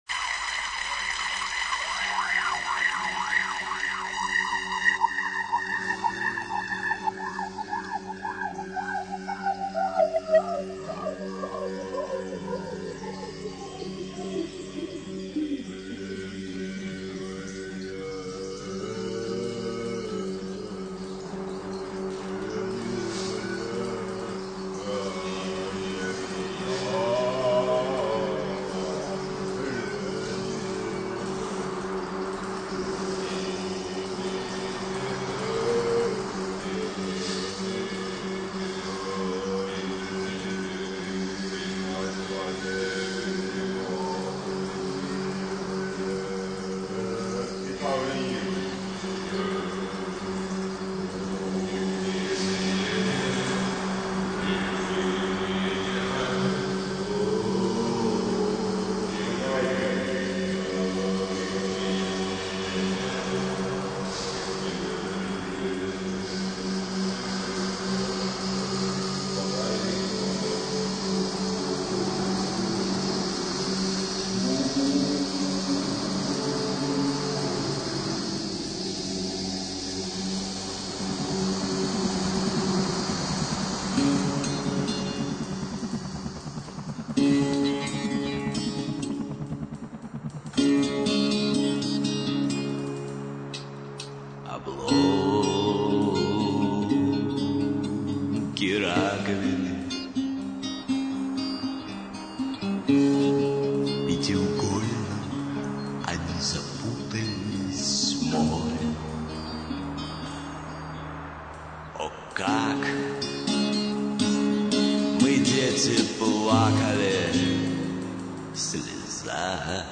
В качестве заставки испозьзовано сэмплированное пение
Всю композицию (mono, 40 kbps, 1019 kb) вы можете скачать